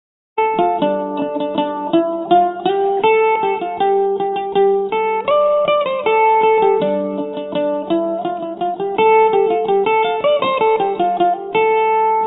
The first is the low sample rate original: